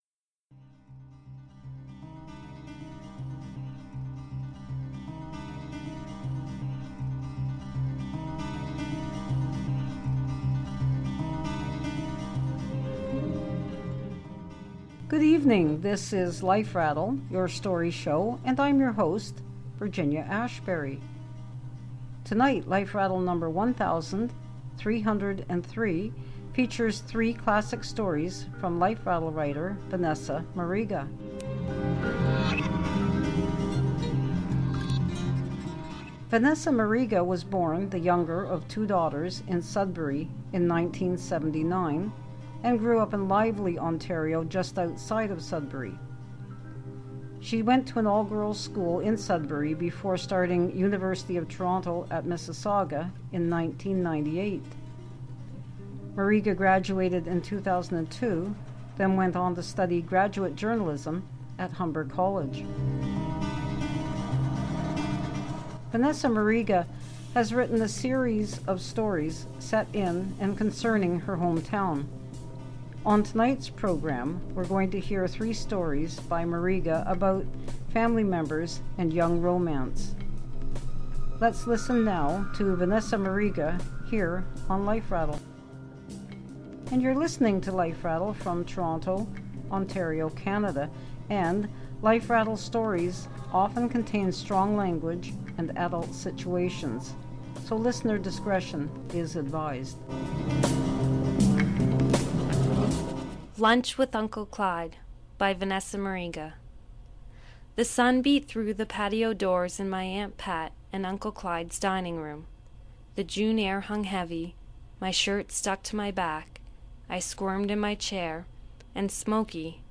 Please note: tonight’s stories contain adult situations and strong language.